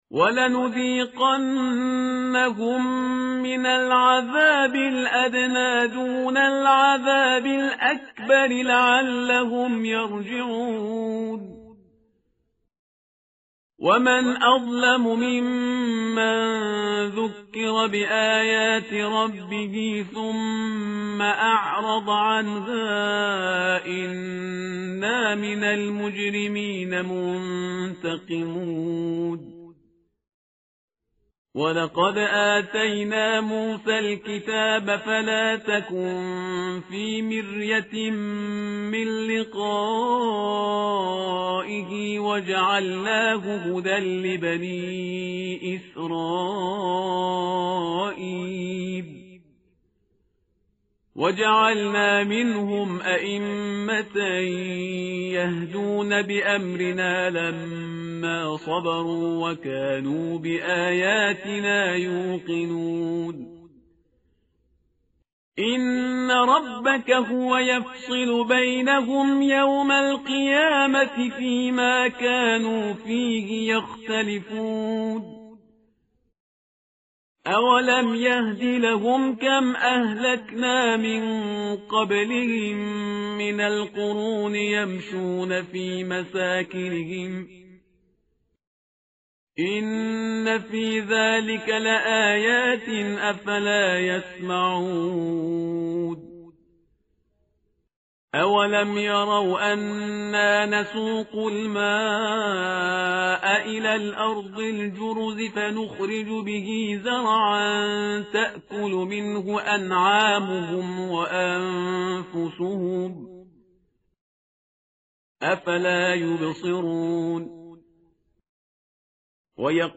tartil_parhizgar_page_417.mp3